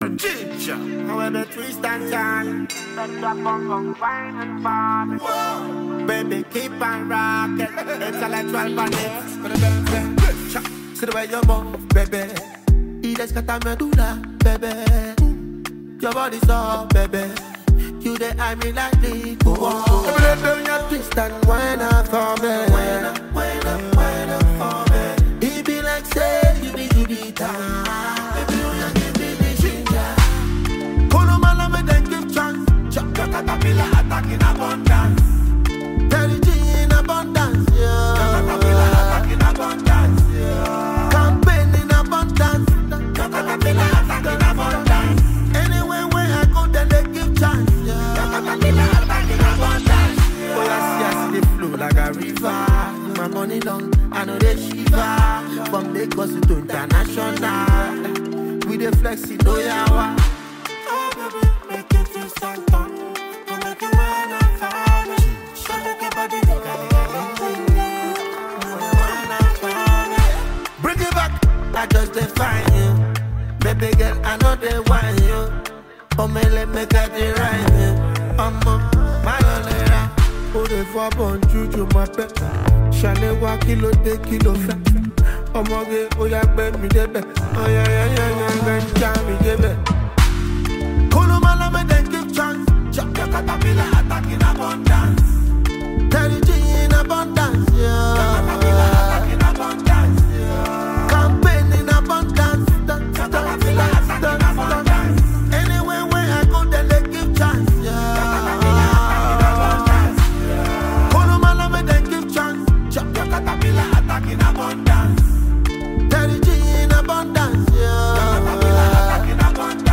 mid-tempo Dancehall-fused love banger